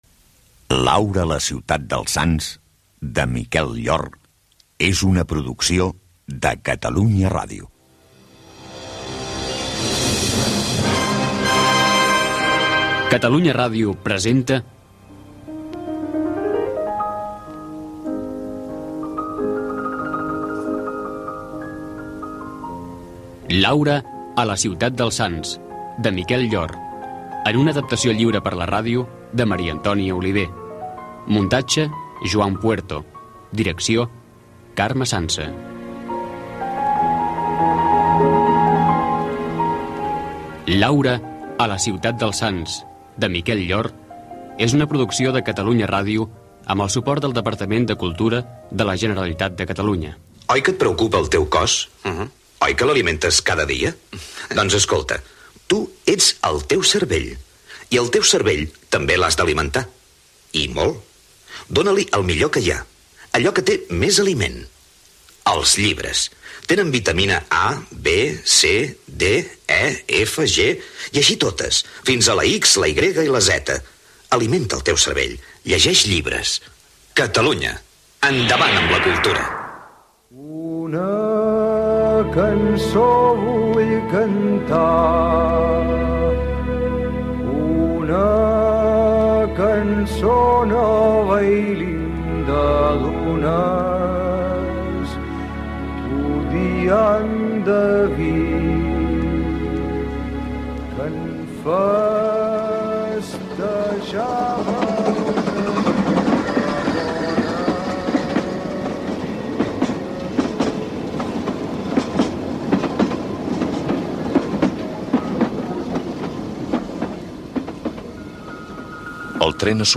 Careta del programa amb l'equip. Publicitat. Adaptació radiofònica de l'obra "Laura a la ciutat dels sants", de Miquel Llor.
Ficció
El serial radiofònic s'emetia dins del programa "Adelina Boulevard".